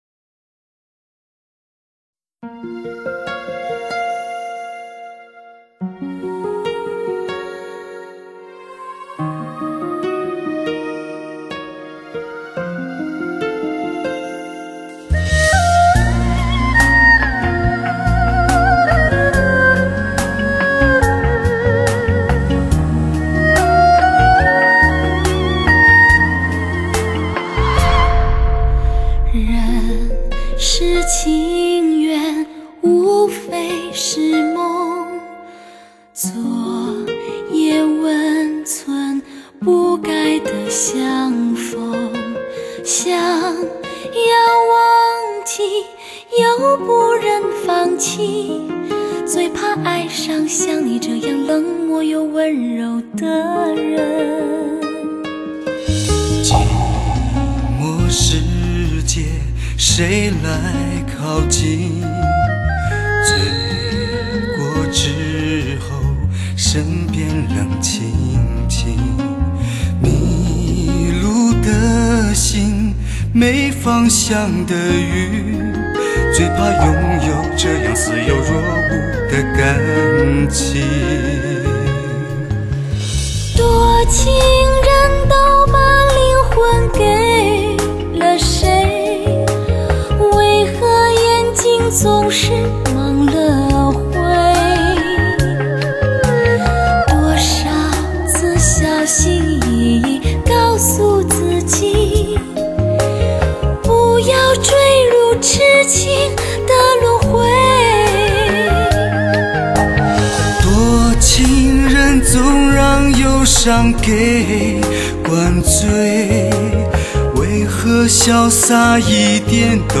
真正实现高清，全方位360环绕3D立体音效。